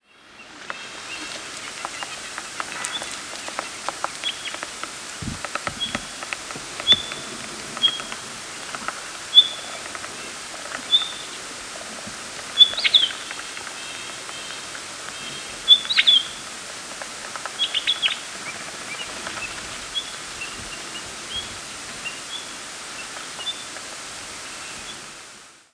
Pine Grosbeak diurnal flight calls
Diurnal calling sequences: